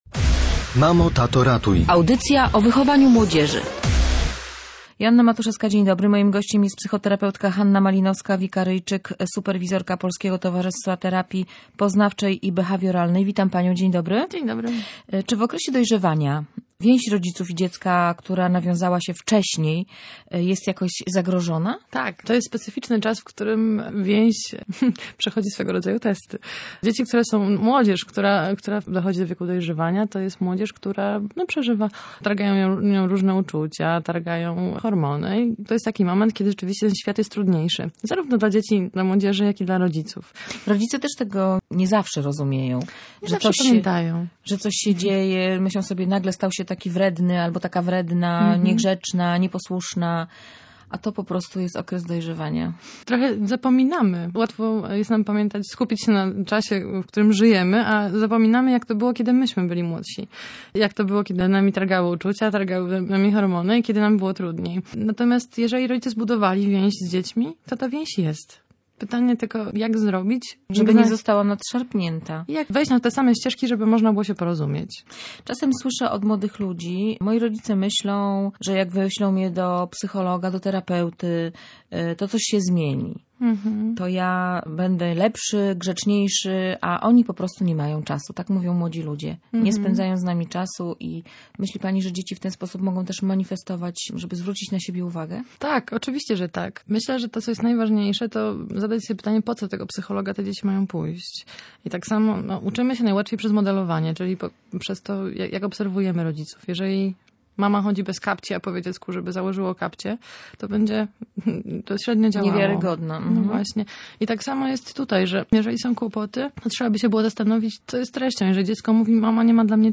Więź dzieci rodziców w okresie dojrzewania stale poddawana jest egzaminowi - mówiła w Radiu Gdańsk psychoterapeutka